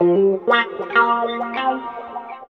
90 GTR 3  -R.wav